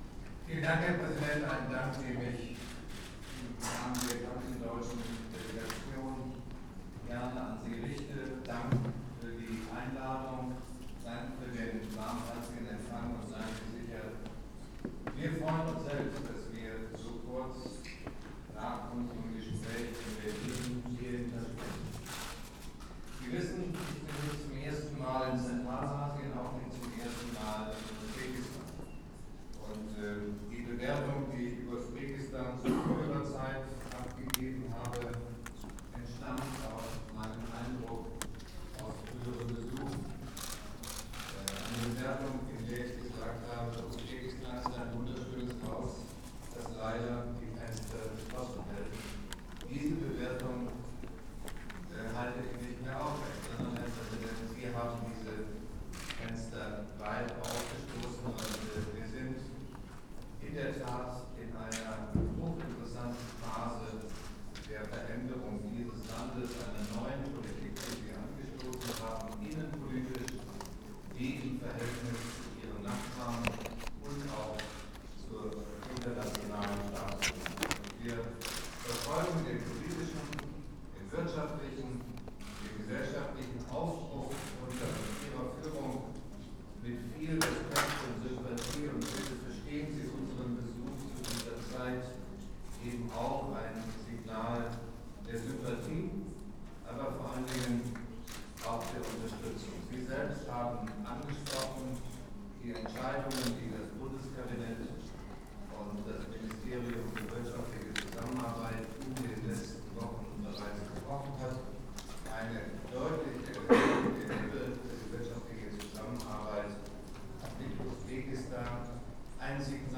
Der Bundespräsident am runden Tisch an die Gastgeber